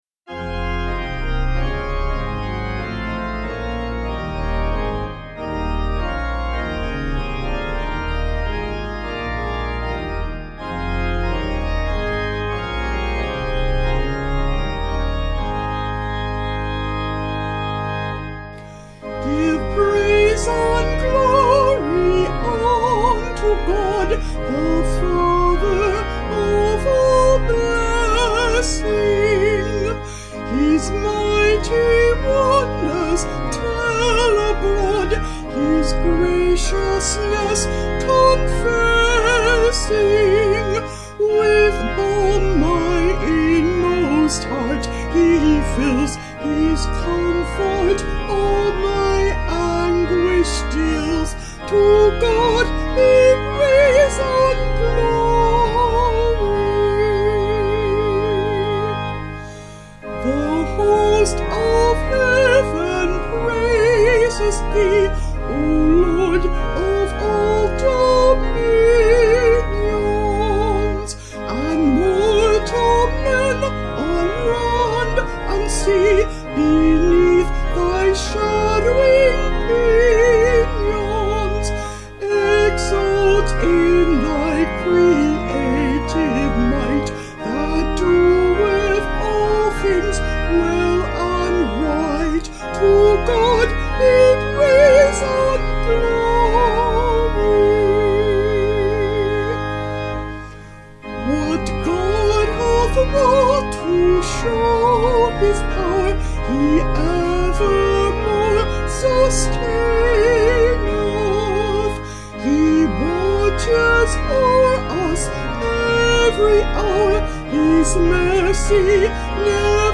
(CW)   3/Ab
Vocals and Organ   240.5kb Sung Lyrics